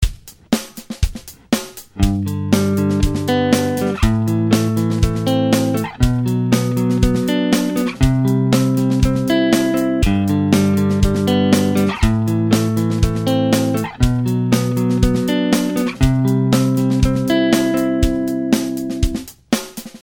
Arpeggios With Bar Chords | Download
barchords.mp3